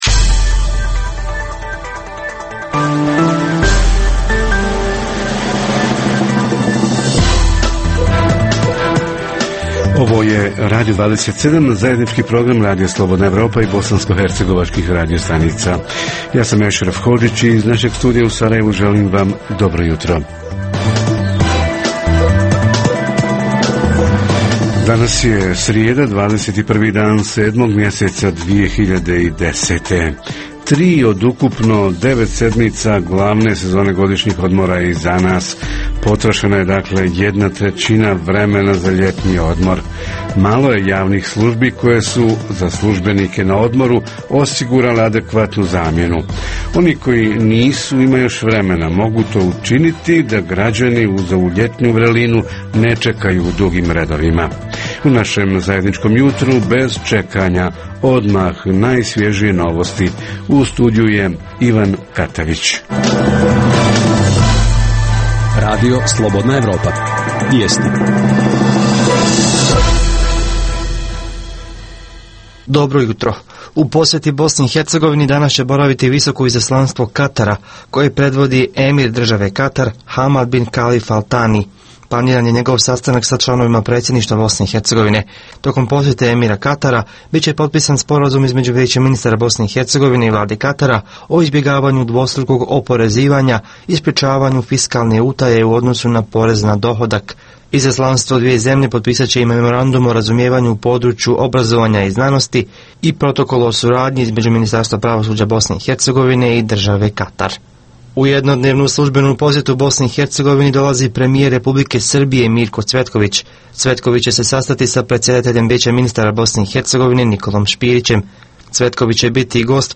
“Crne tačke”, dionice saobraćajnica na kojima su najučestalije nesreće s najtežim posljedicama – kako saobraćaj učiniti sigurnijim i za vozače i njihove saputnike, i za pješake? Reporteri iz cijele BiH javljaju o najaktuelnijim događajima u njihovim sredinama.